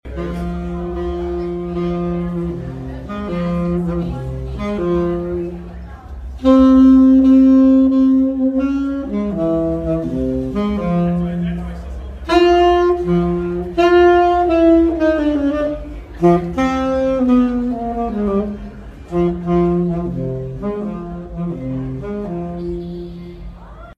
sound so smooth
sax